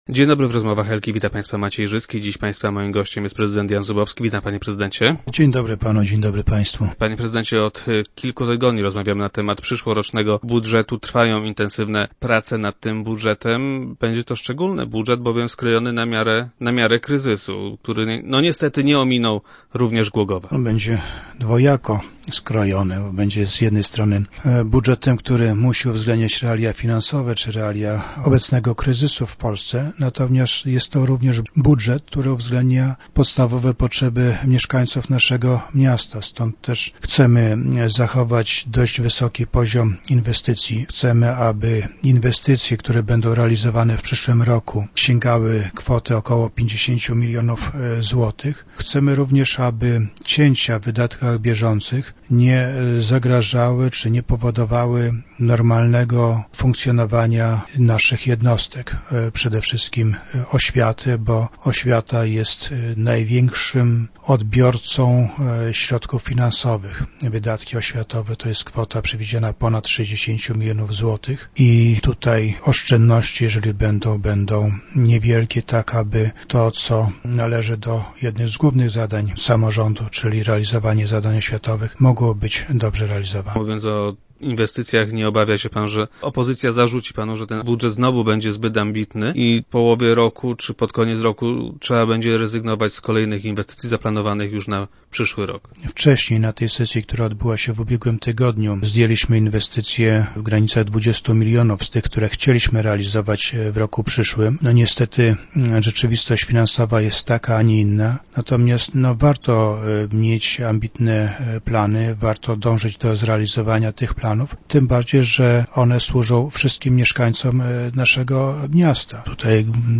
- Planujemy w przyszłym roku zaciągnąć kredyt w wysokości 30 milionów złotych, a właściwie chcemy wpisać do budżetu możliwość zaciągnięcia takiego kredytu. Chcąc zapiąć budżet, deficyt będziemy musieli pokryć kredytem. Wszystko jednak wskazuje na to, że środki zewnętrzne, jakie możemy pozyskać, pozwolą na znaczne zmniejszenie jego wysokości - powiedział prezydent Zubowski, który był dziś gościem Rozmów Elki.